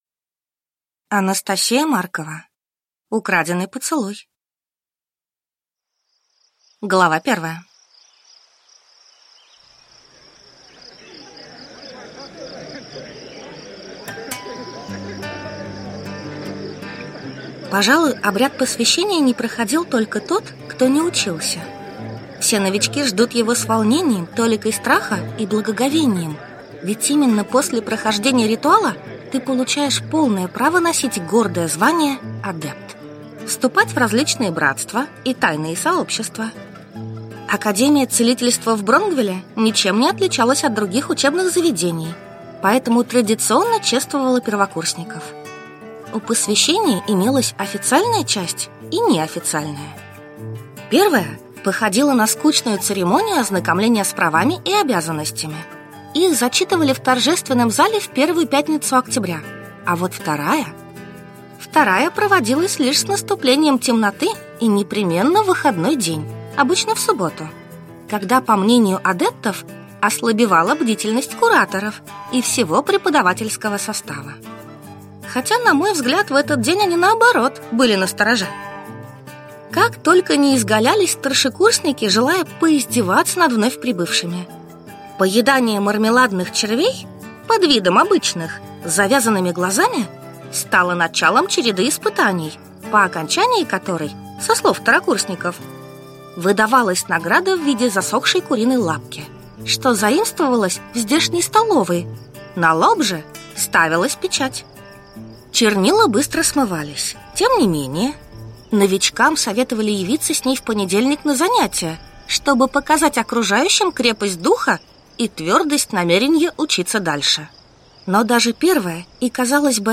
Аудиокнига Украденный поцелуй | Библиотека аудиокниг